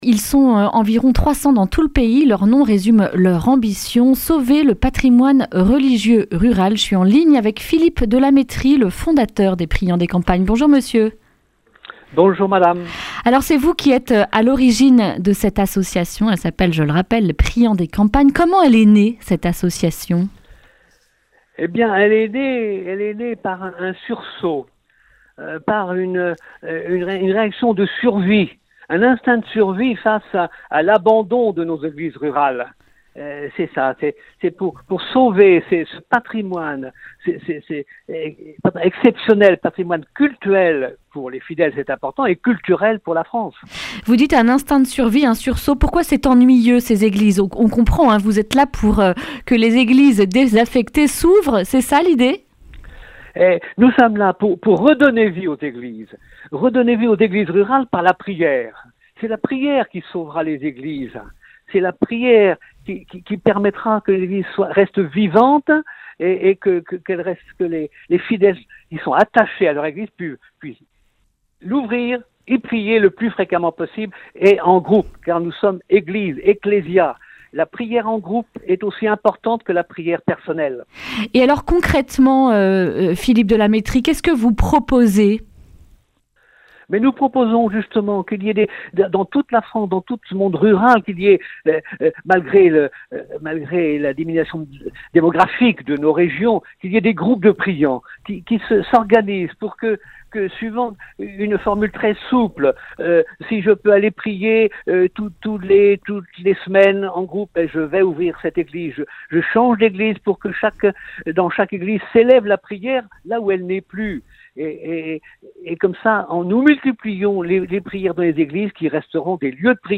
vendredi 6 mars 2020 Le grand entretien Durée 10 min